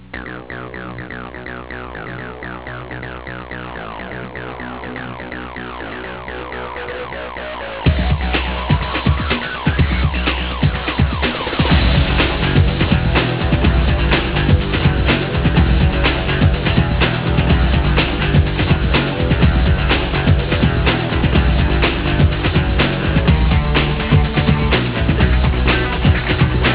From which TV program or film is this theme tune from?